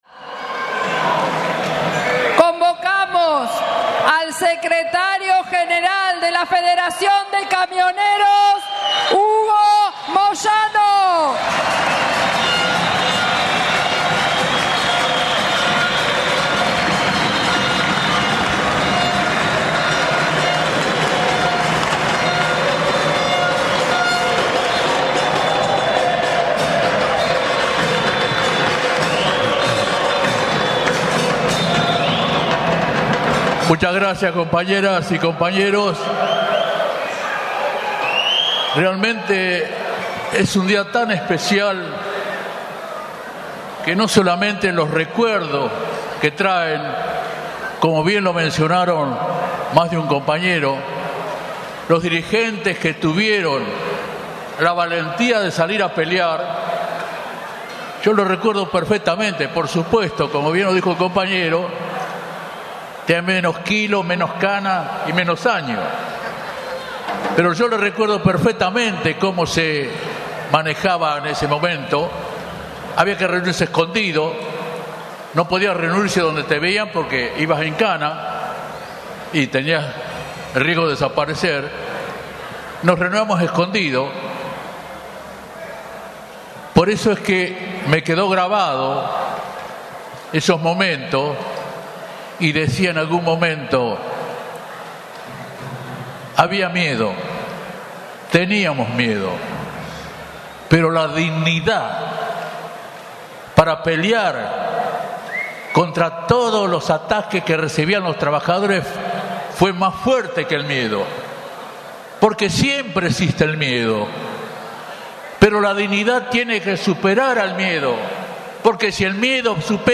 Discurso de Moyano:
Durante toda la liturgia del movimiento obrero y del peronismo se vivió a puro clamor de los bombos y cánticos, también sonaron fuertes consignas contra el gobierno conducido por de Mauricio Macri.